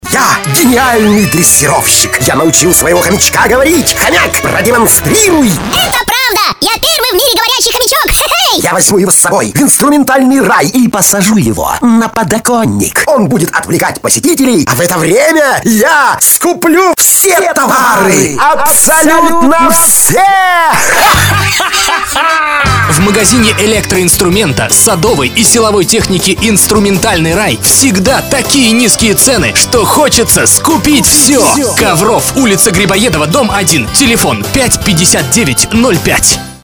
Рекламные ролики, компьютерные игры, пародии, радио-бренд войс График работы: с 8-00 до 22-00 мск.
Тракт: Октава МК-319А,FastTrackPro